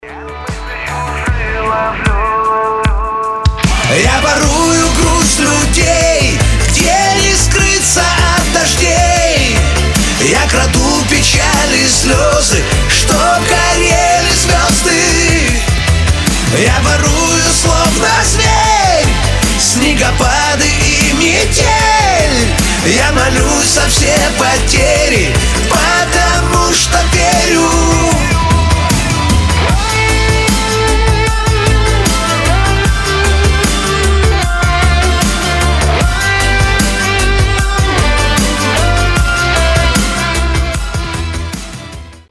• Качество: 320, Stereo
поп
эстрадные
воодушевляющие